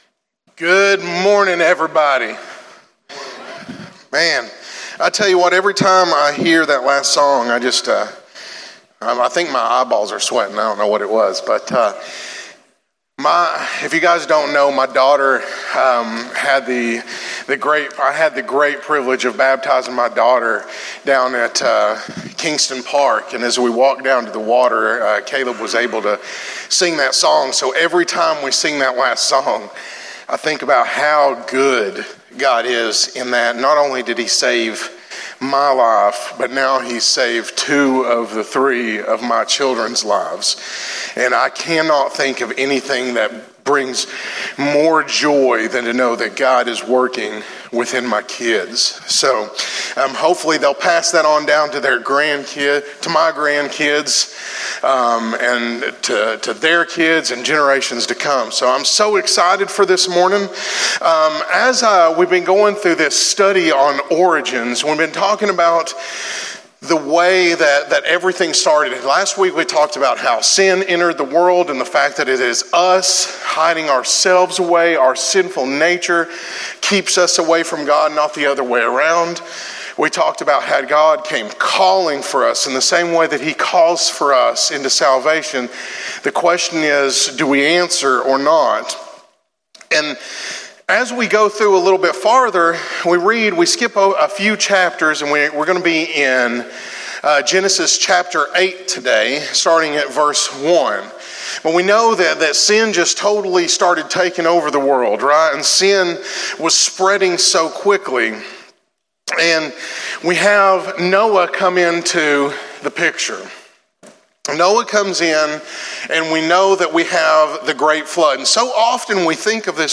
sermon.cfm